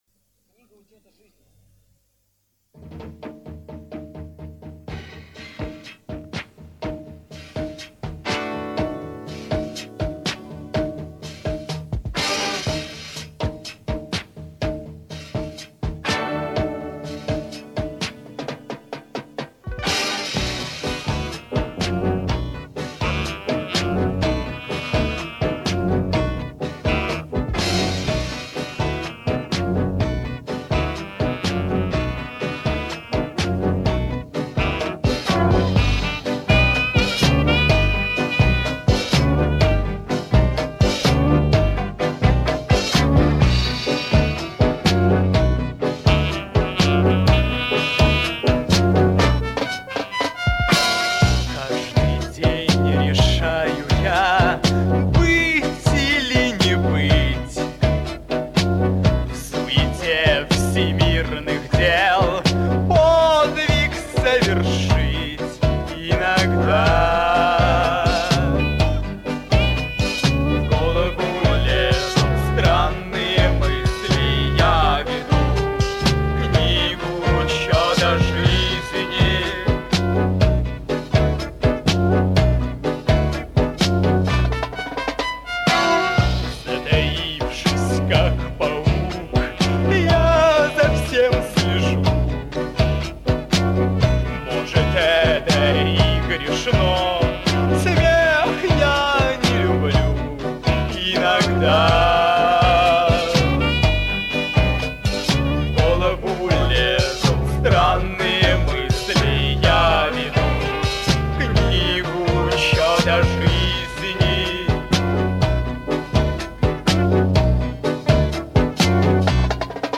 Рок Русский рок